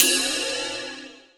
FX_RIDE.wav